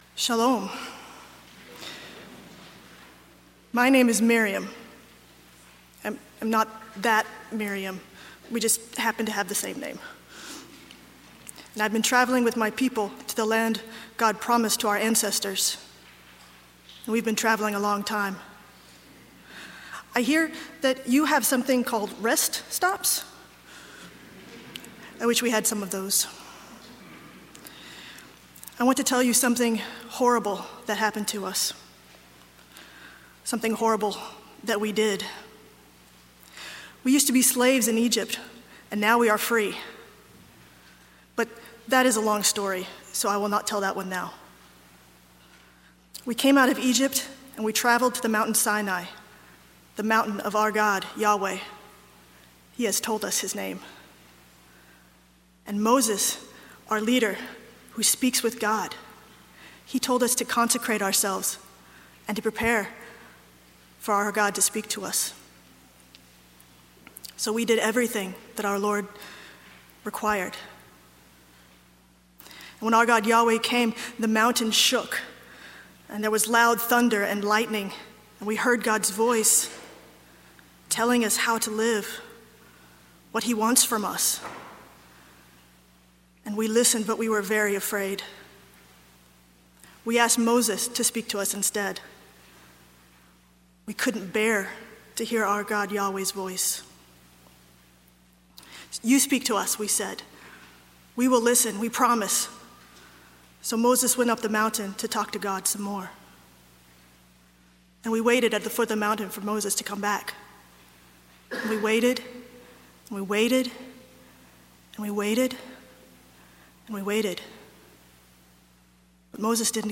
I begin this sermon preaching in the first person. There is a long pause in the middle where I am taking off the Bible time clothes and putting my jacket on.